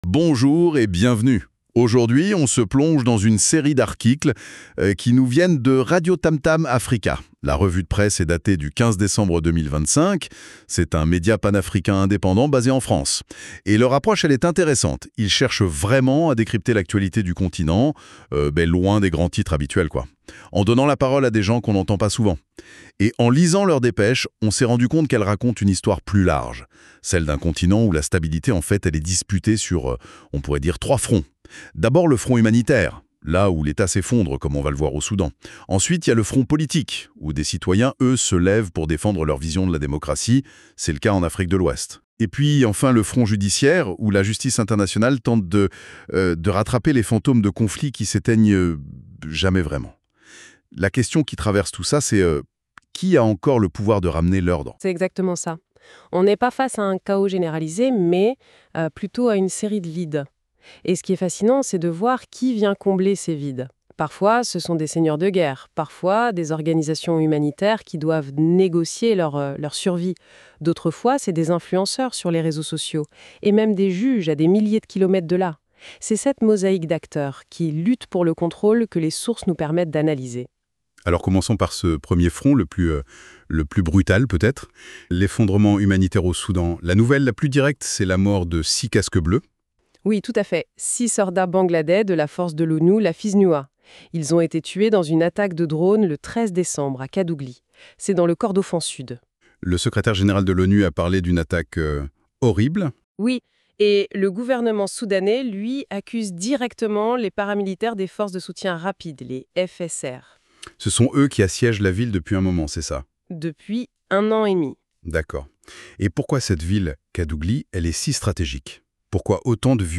Une revue de presse